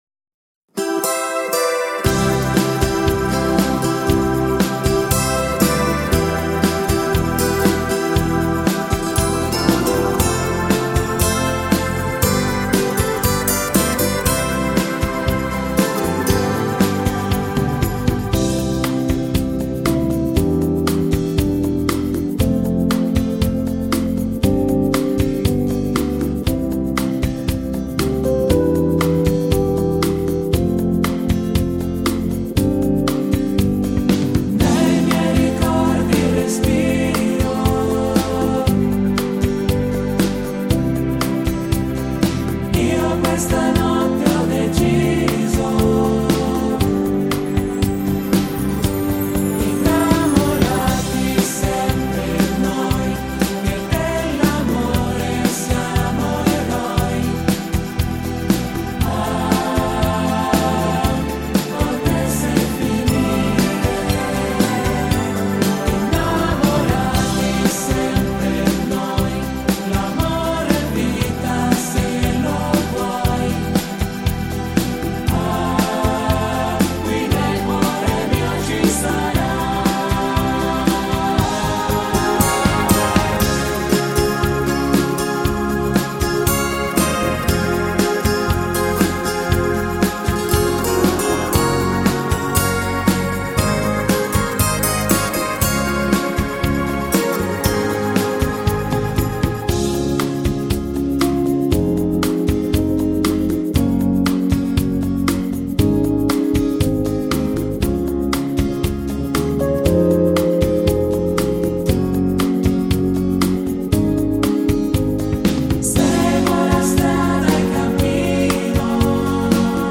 Base in tonalità maschile